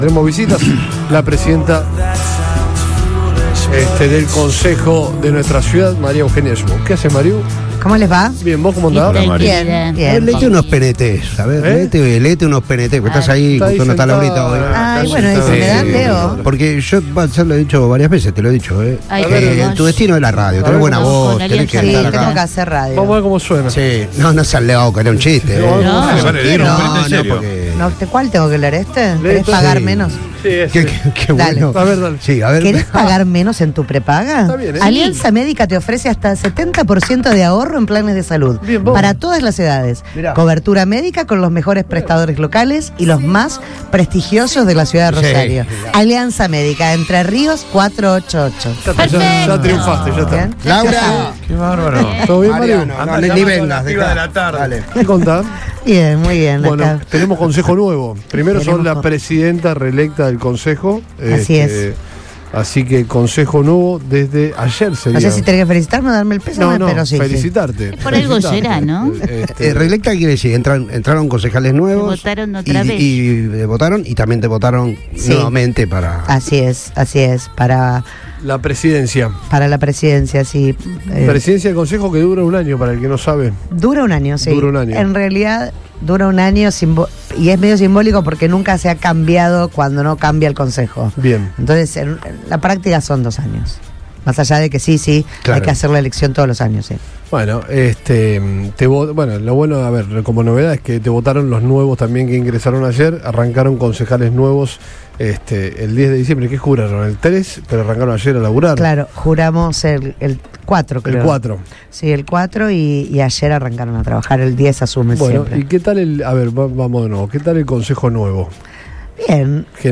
La presidenta del Concejo Municipal de Rosario visitó los estudios de Radio Boing y dialogó sobre el nuevo cuerpo legislativo y los temas centrales que se debatirán en 2026.